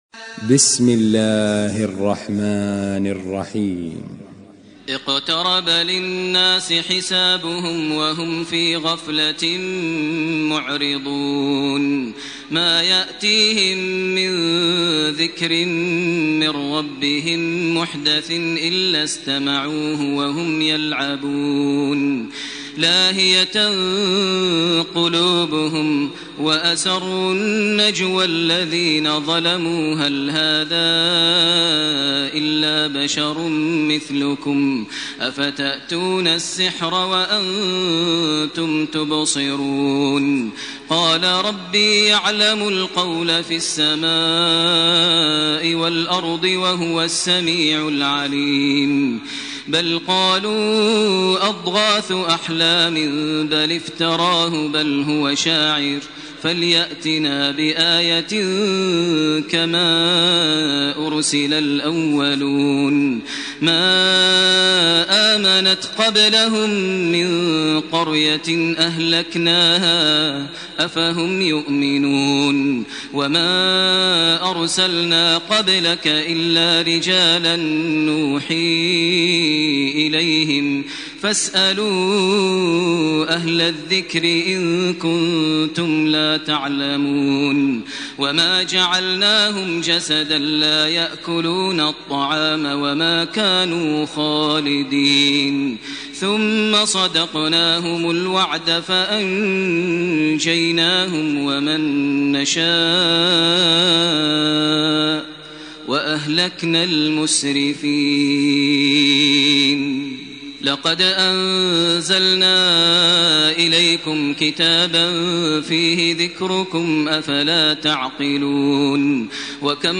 سورة الأنبياء > تراويح ١٤٢٨ > التراويح - تلاوات ماهر المعيقلي